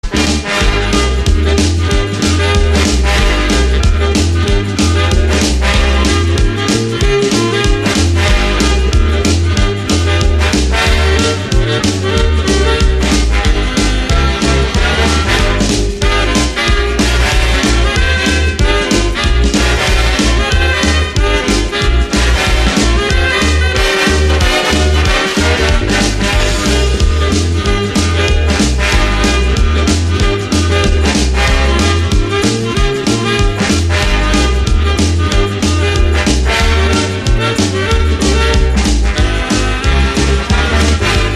энергичные
танцевальные
ретро
Энергичная танцевальная ретро-мелодия